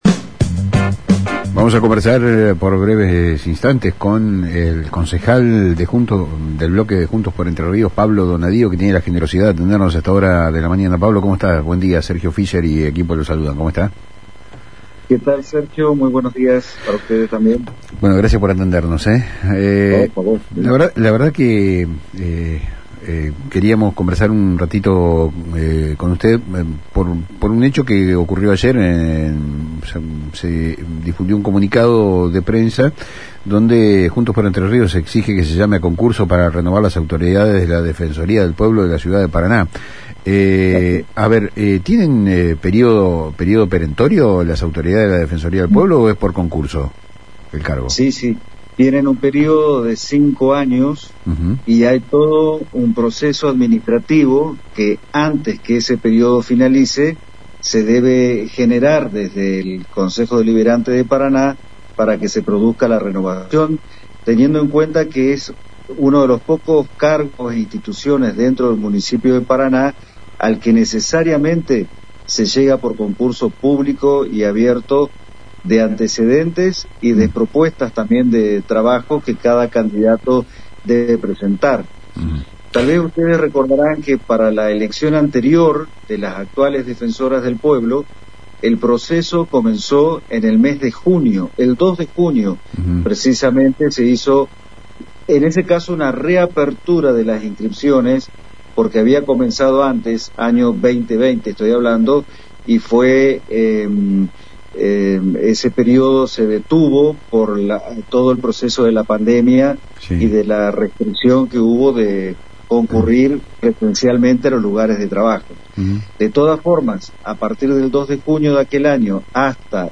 En una entrevista radial con Palabras Cruzadas por FM Litoral, el concejal Pablo Donadío subrayó que la Defensoría es una de las pocas instituciones municipales a las que se accede por concurso de antecedentes.